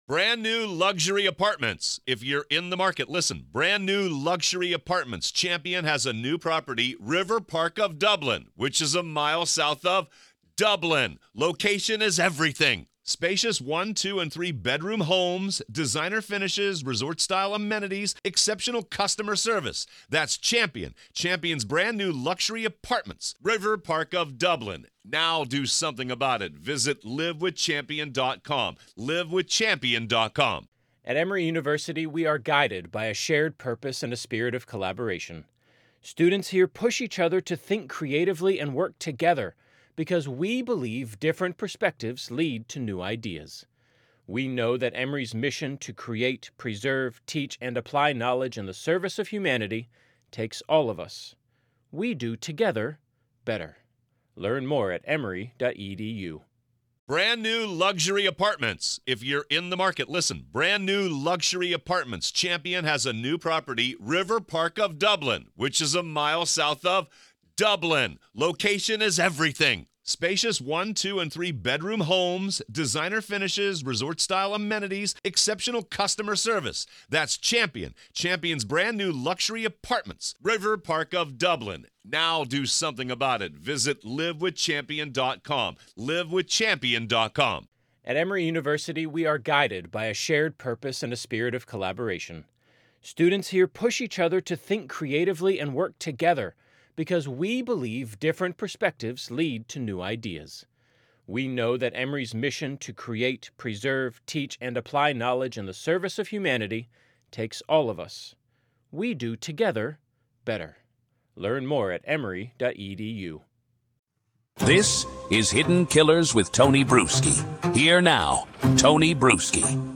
In this full-length interview